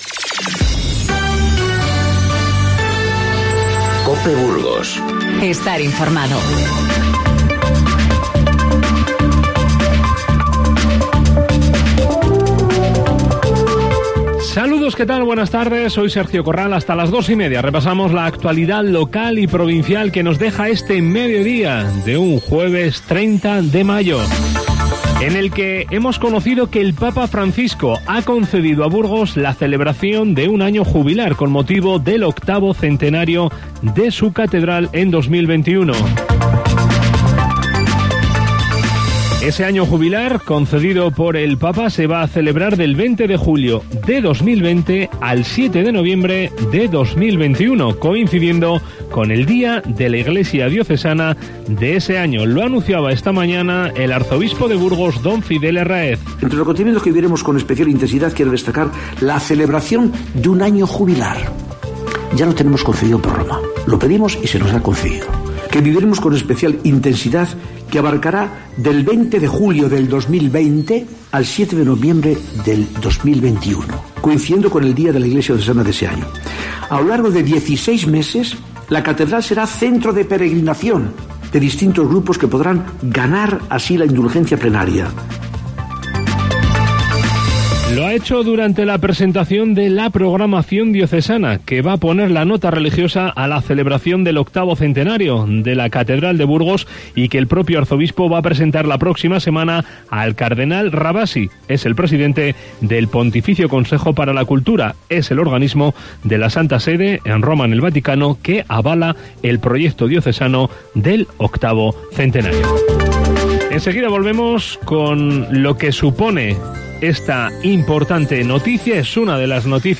Informativo Mediodía COPE Burgos 30/05/19